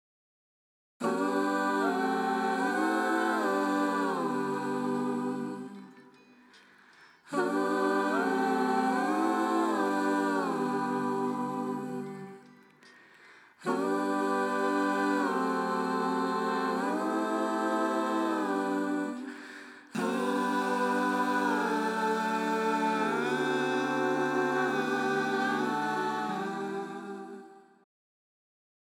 Processing of backing vocals.
This is the dry file: